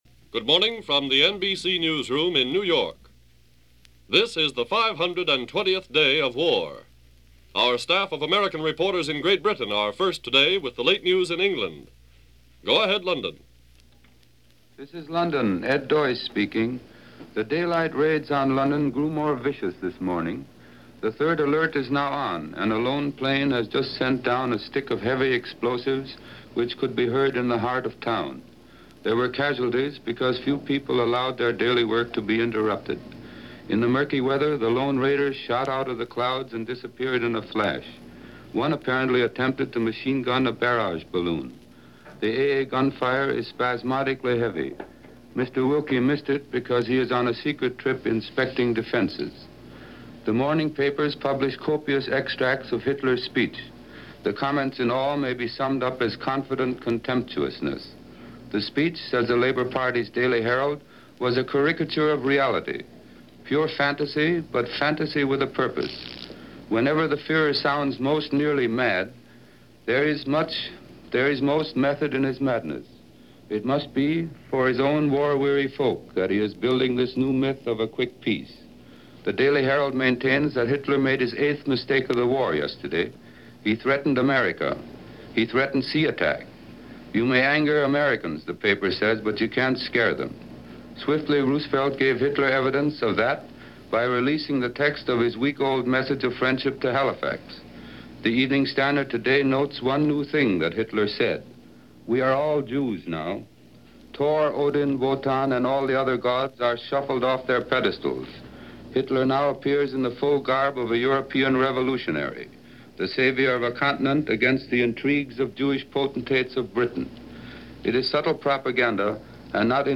And Counting - January 31, 1941 - news from the various battle fronts as well as London and America.